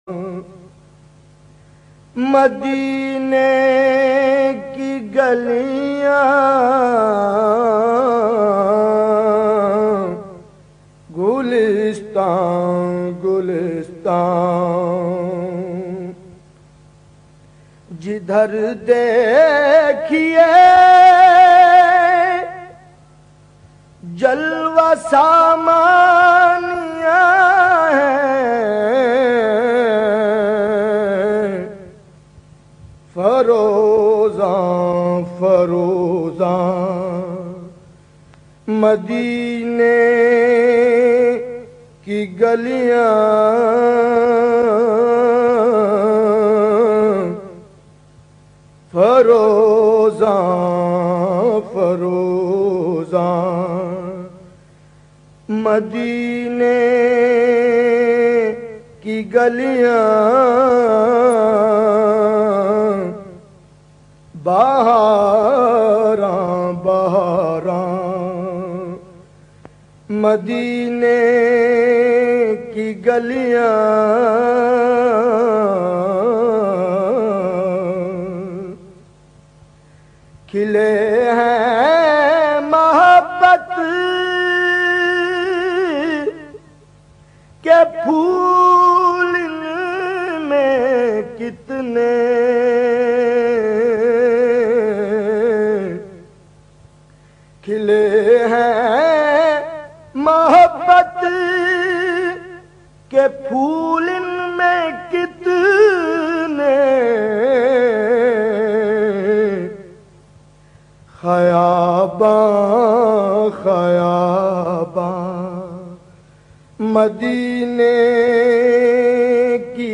Beautifull voice